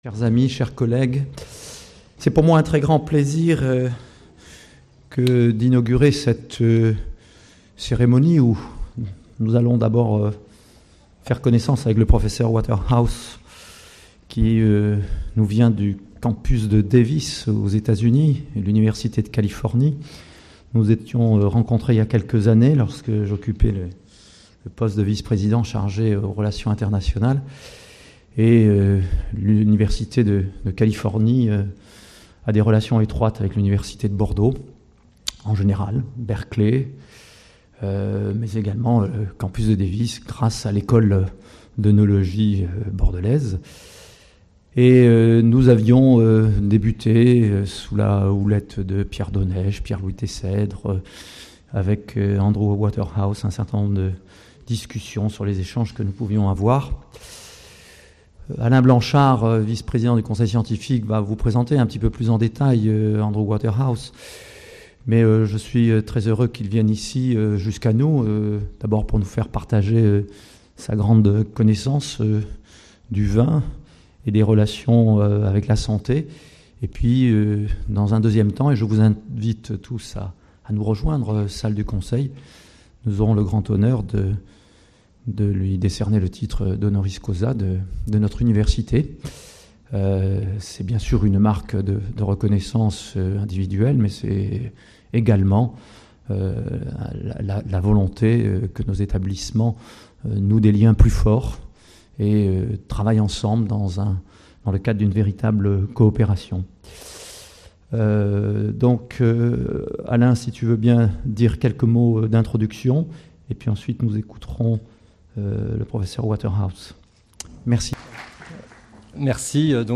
Le vin, un allié thérapeutique de choix ? Cette conférence, qui retrace les moments clés de sa carrière de chercheur, a été donnée